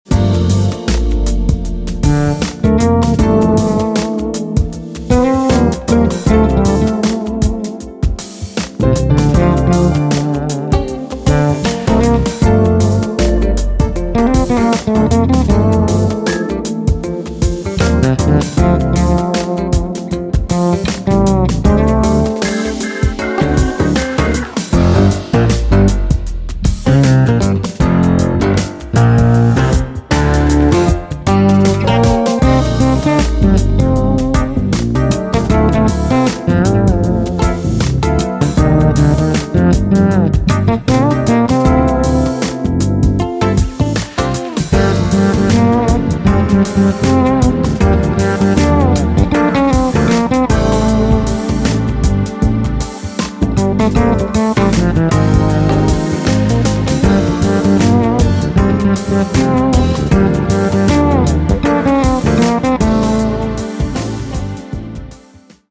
Keyboards, Programming
Fretless Bass, Fender VI, additional Keyboards